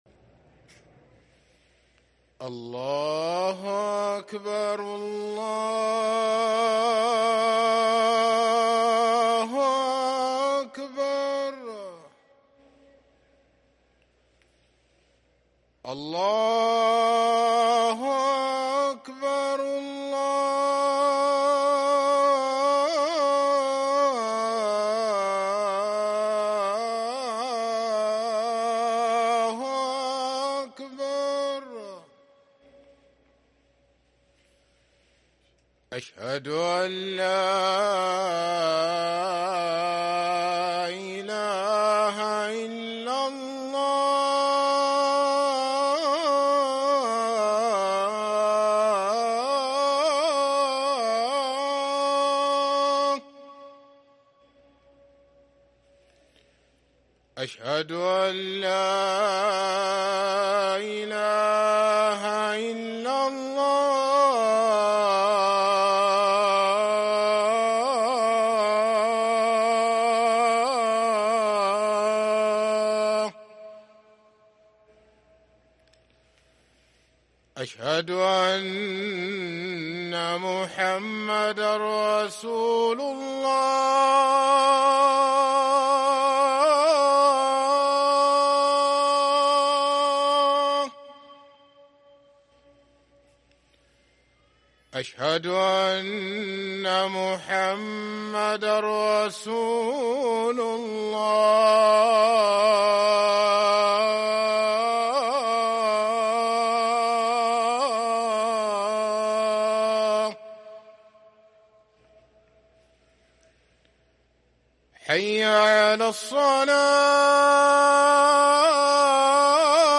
اذان العصر